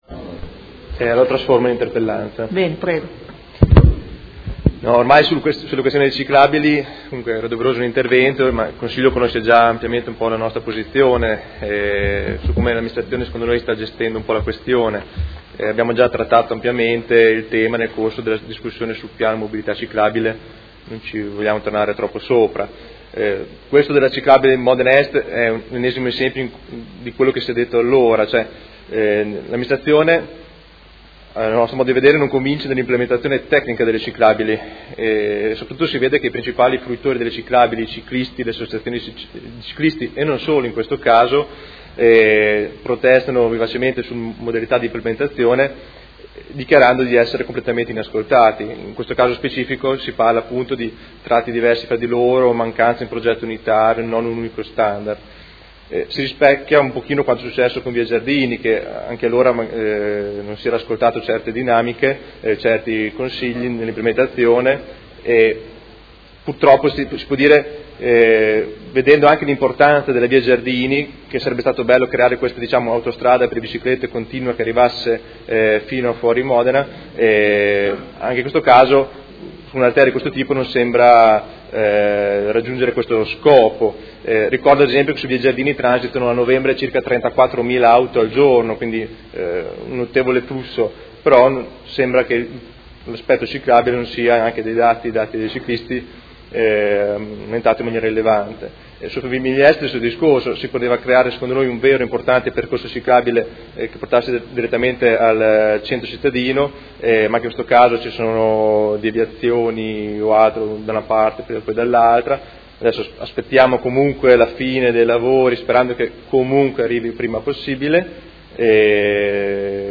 Marco Rabboni — Sito Audio Consiglio Comunale
Seduta del 11/05/2015 Interrogazione dei Consiglieri Arletti, Carpentieri e Fasano (PD) avente per oggetto: Ciclabile su Via Emilia Est – quali i tempi per dare continuità al percorso. Trasformata in interpellanza su richiesta del Consigliere Carpentieri.